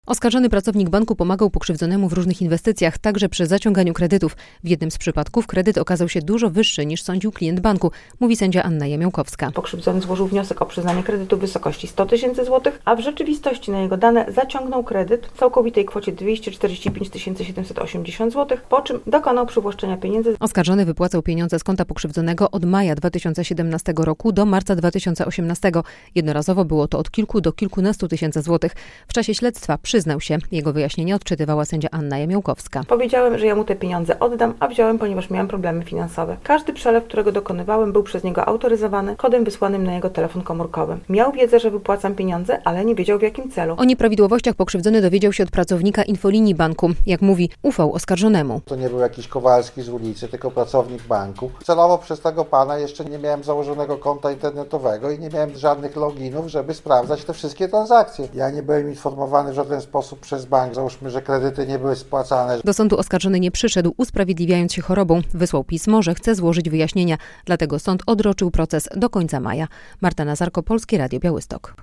Rozpoczął się proces pracownika banku, który przywłaszczał sobie pieniądze z konta klienta - relacja